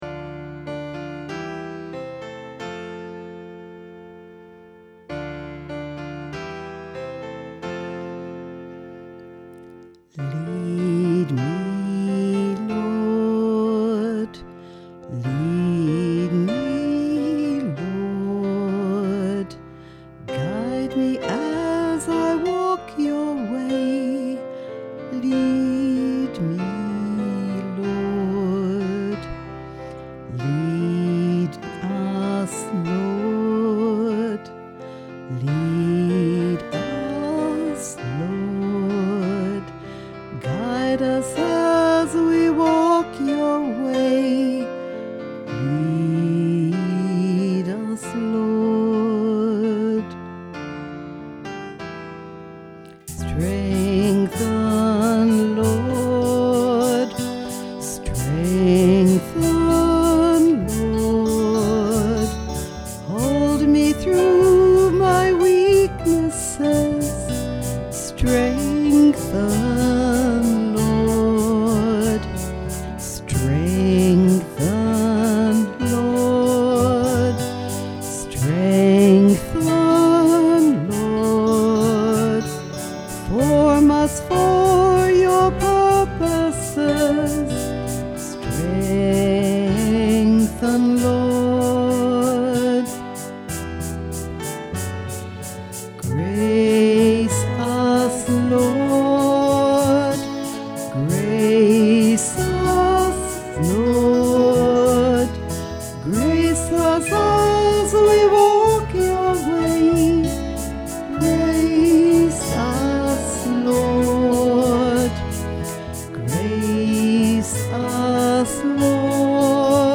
vocal (in F)